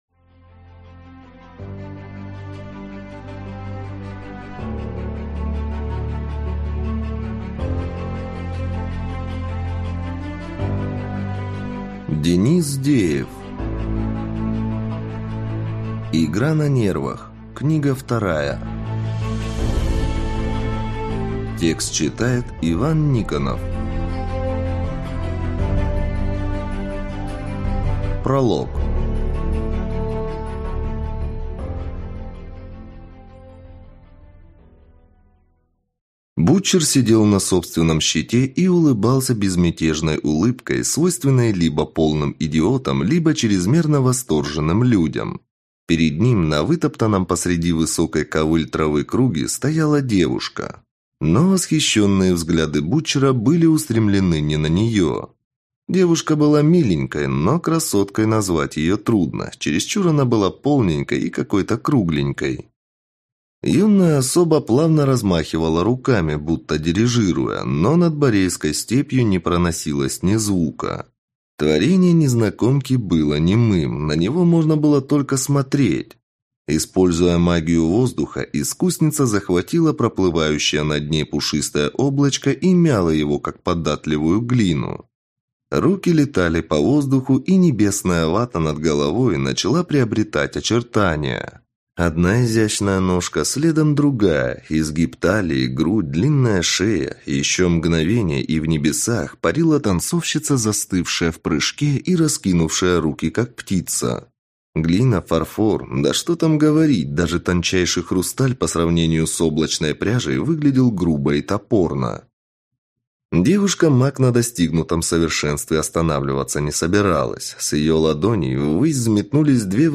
Аудиокнига Игра на нервах.